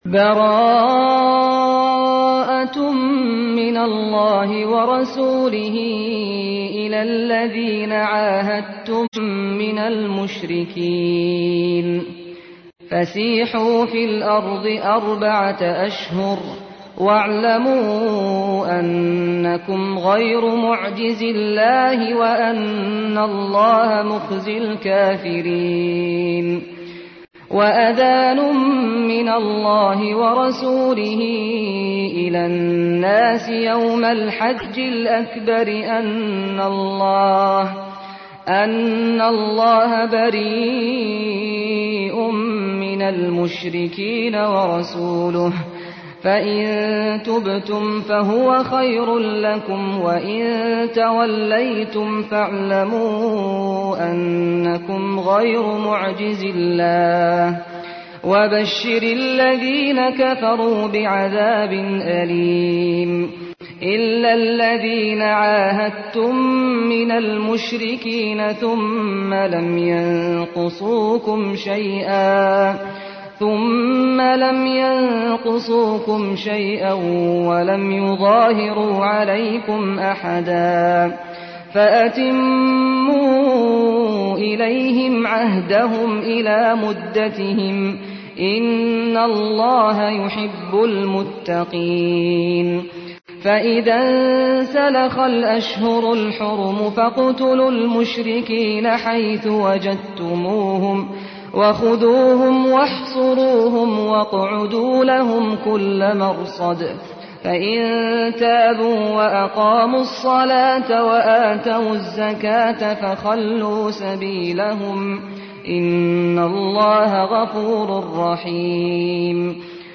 تحميل : 9. سورة التوبة / القارئ سعد الغامدي / القرآن الكريم / موقع يا حسين